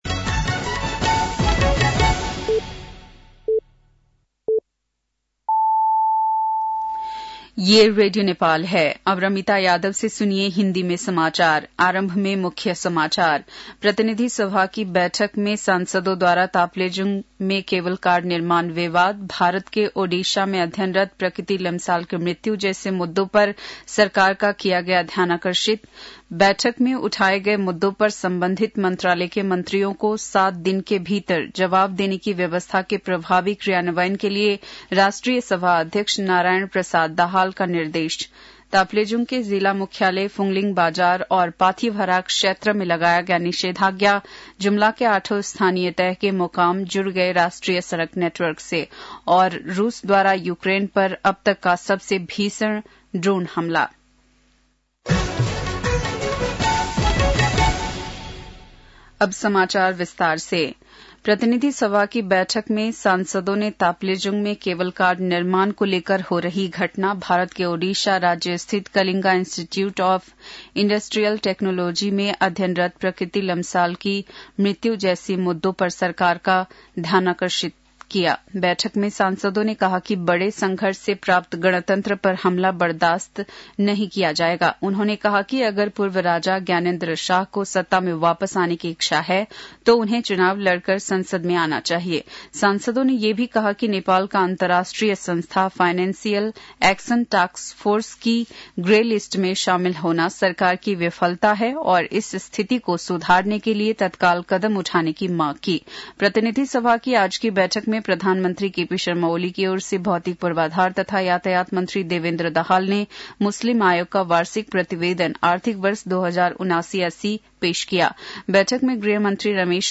बेलुकी १० बजेको हिन्दी समाचार : १२ फागुन , २०८१